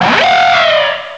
pokeemerald / sound / direct_sound_samples / cries / archeops.aif